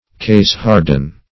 Search Result for " caseharden" : The Collaborative International Dictionary of English v.0.48: Caseharden \Case"hard`en\, v. t. 1.
caseharden.mp3